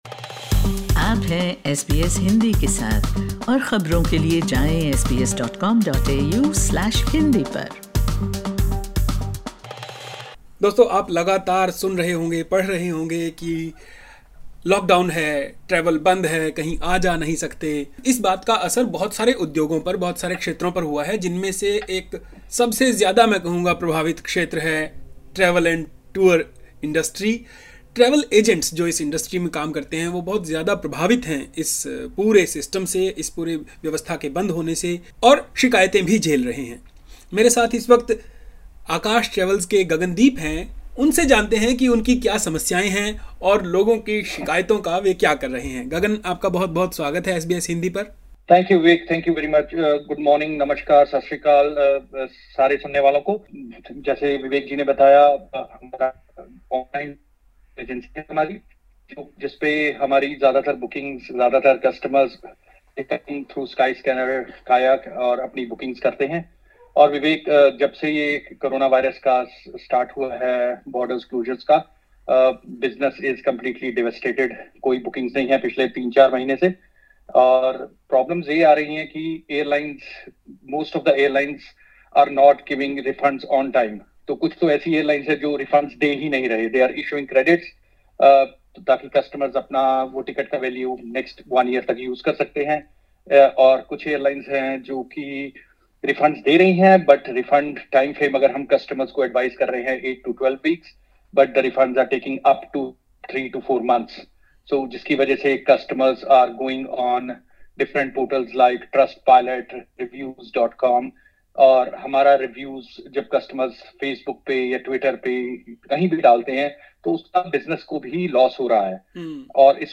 SBS हिन्दी